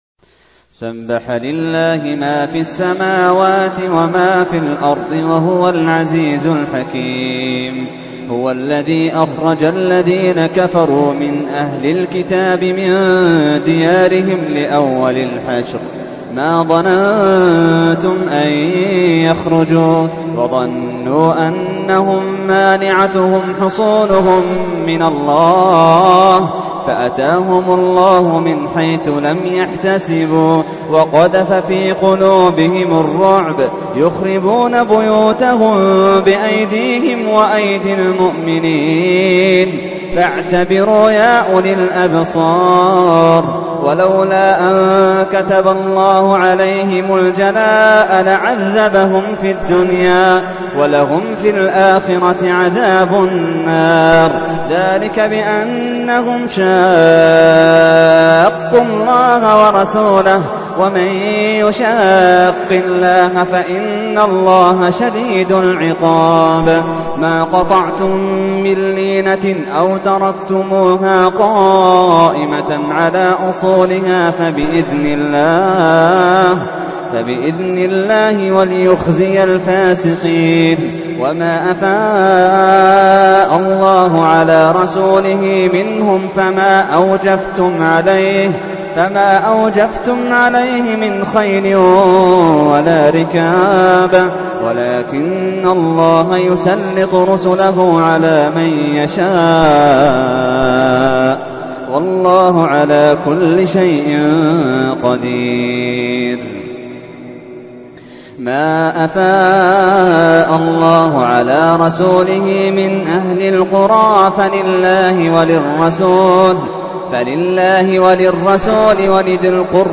تحميل : 59. سورة الحشر / القارئ محمد اللحيدان / القرآن الكريم / موقع يا حسين